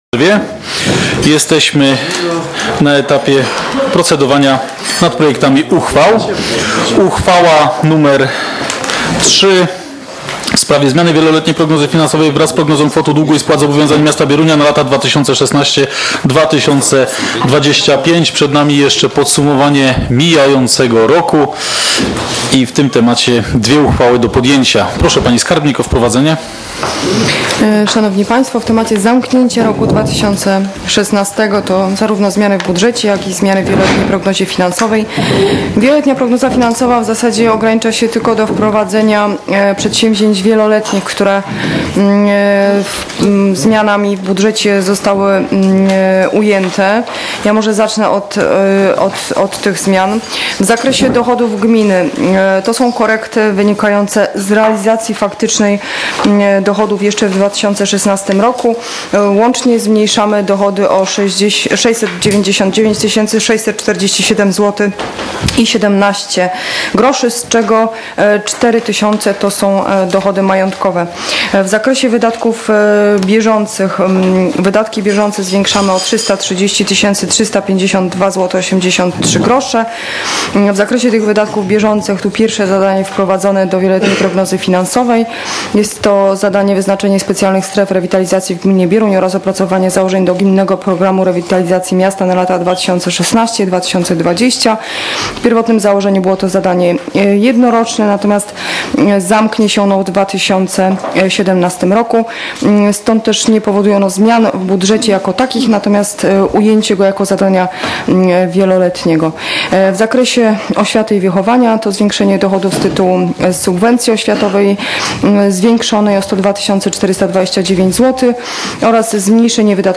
z obrad XII sesji Rady Miejskiej w Bieruniu, która odbyła się w dniu 29.12.2016 r.